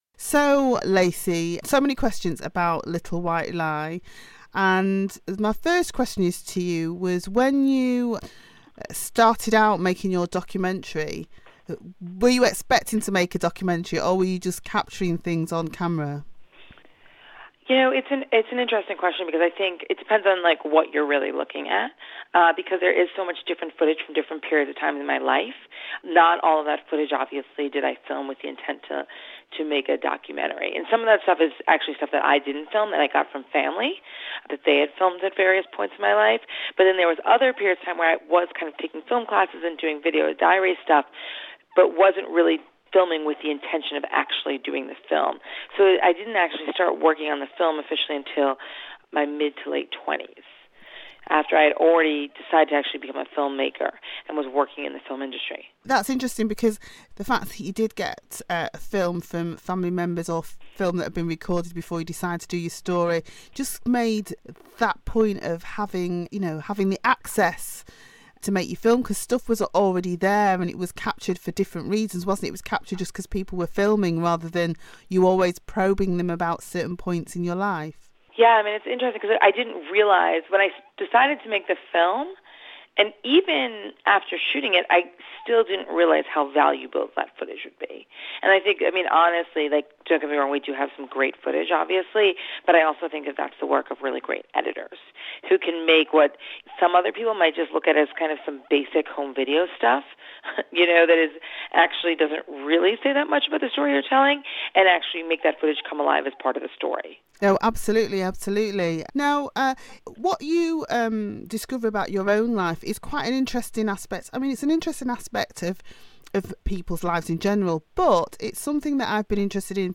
Part 1 of the interview about Little White Lie.